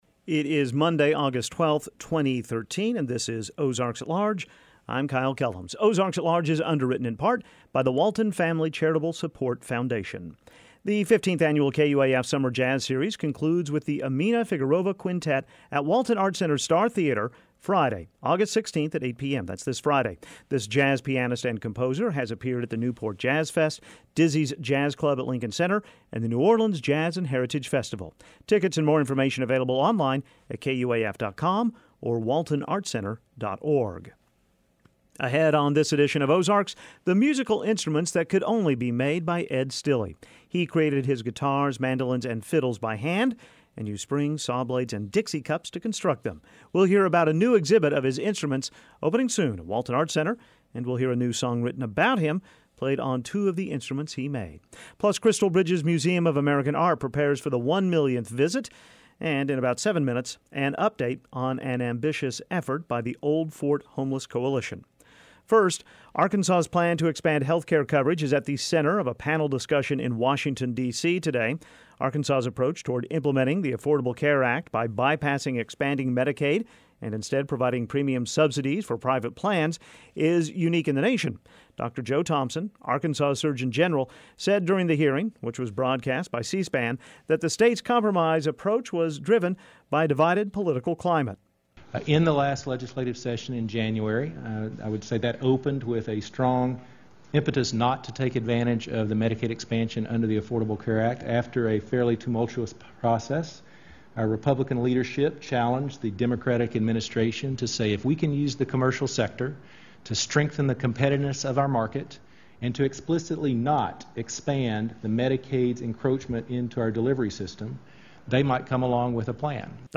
We'll hear about a new exhibit of his instruments opening soon at Walton Arts Center and we'll hear a new song written about him, played on two of the instruments he made. Plus crystal Bridges Museum of American Art prepares for the one millionth visit and an update on an ambitious effort by the Old Fort Homeless Coalition.